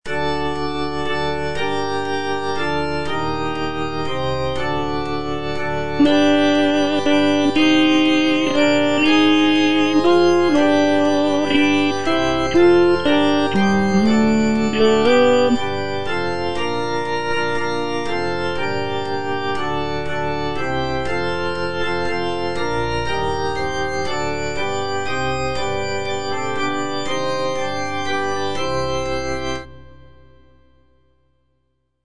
G.P. DA PALESTRINA - STABAT MATER Eja Mater, fons amoris (tenor I) (Voice with metronome) Ads stop: auto-stop
sacred choral work